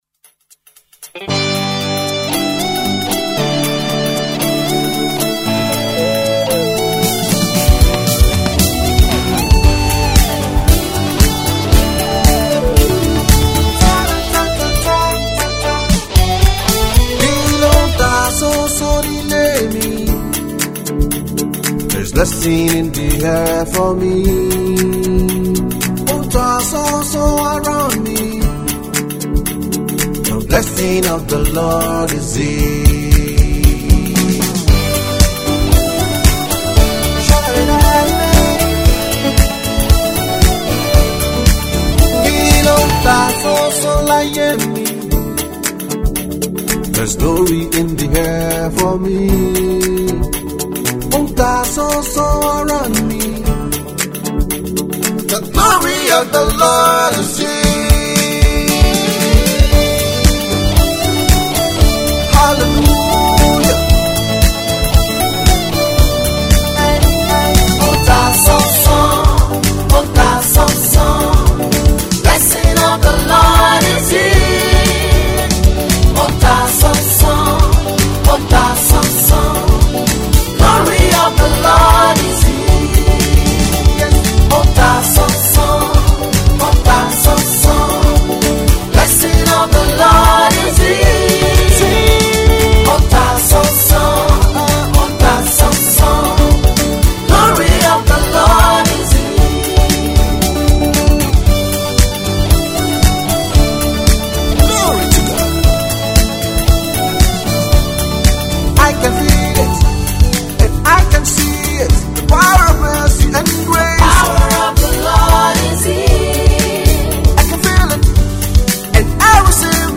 Recording Gospel artiste and music producer